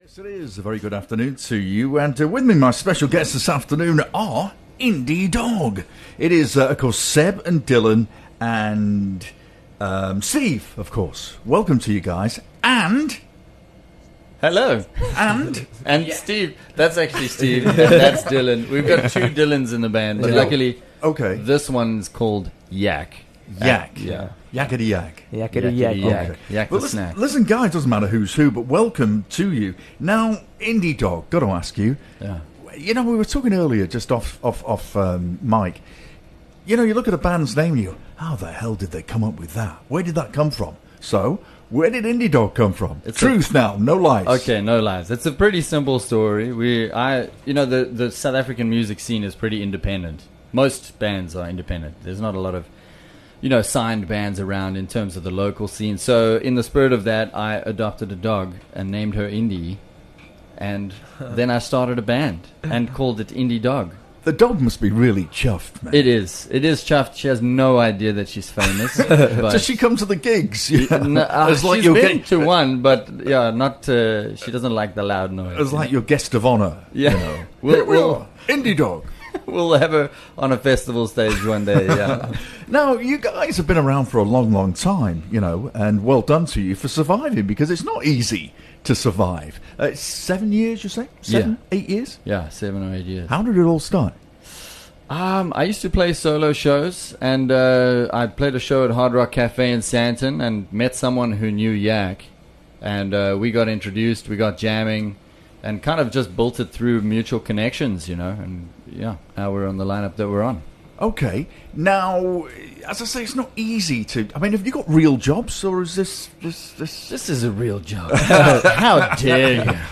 20 Mar Roadtrip Season & Live Energy: In Conversation